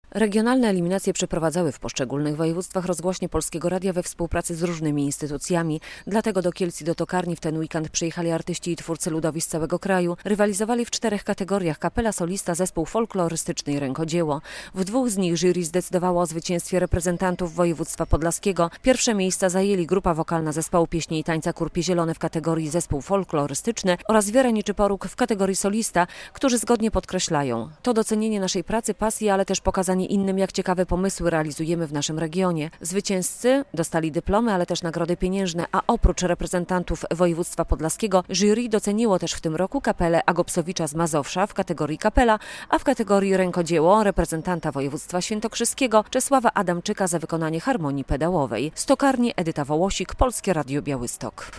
Podlascy artyści z głównymi nagrodami Festiwalu Kultury Ludowej "Jawor - u źródeł kultury" relacja